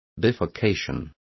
Also find out how bifurcación is pronounced correctly.